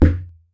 impactPunch_heavy_001.ogg